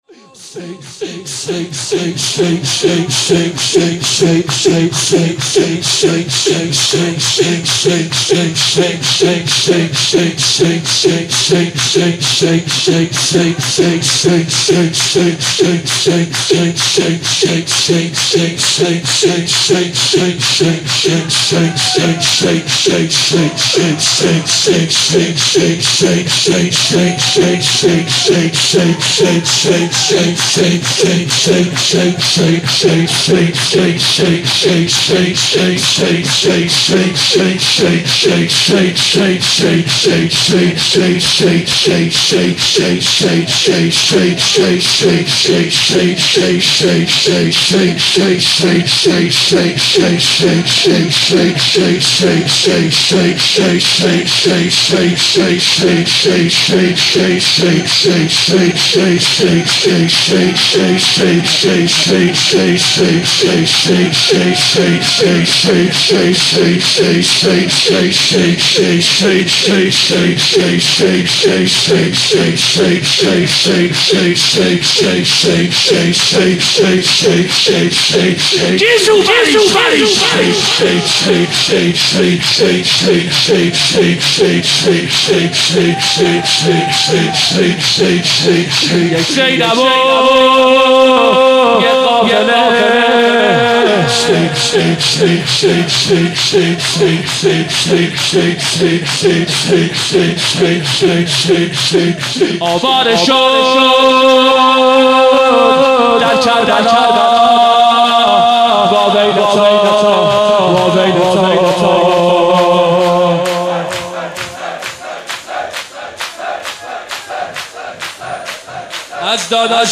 مداح
مناسبت : شب نوزدهم رمضان - شب قدر اول
قالب : شور